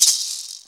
Shaker (5).wav